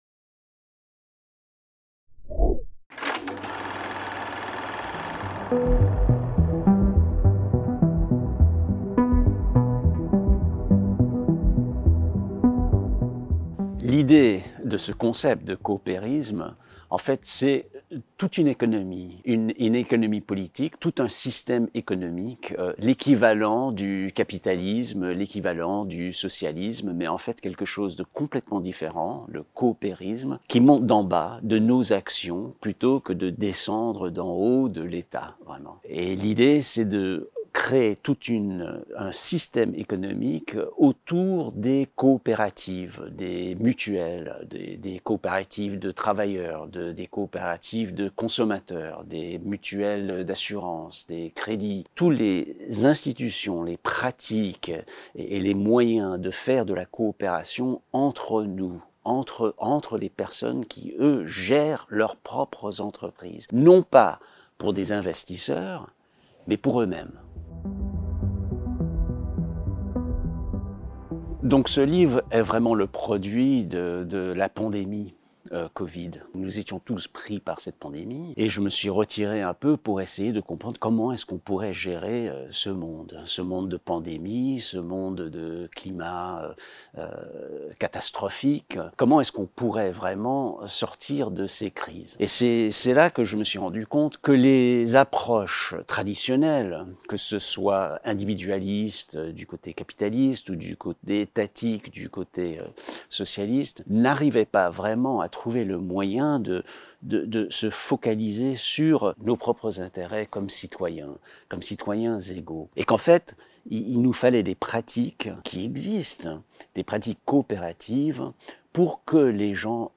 Watch the interview from Fondation Maison des Sciences de l’Homme on Le Coopérisme or listen here: